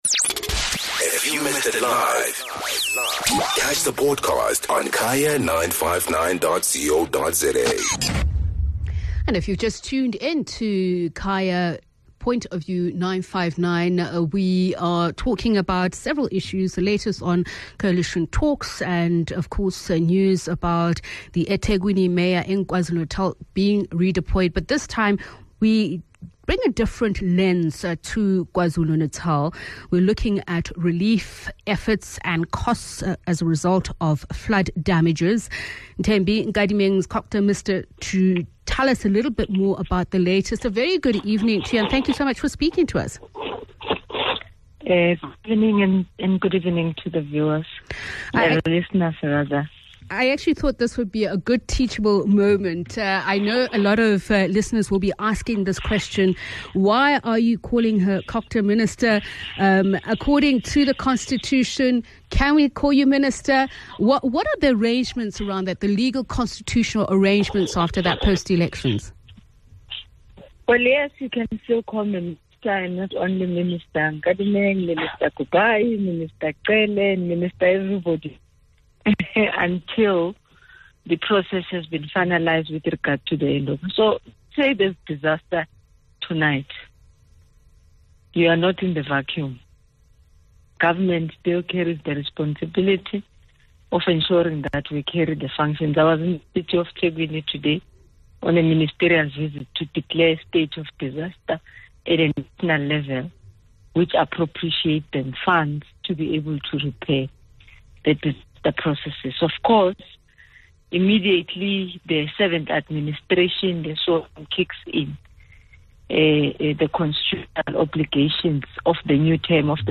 Guest: Thembi Nkadimeng - COGTA Minister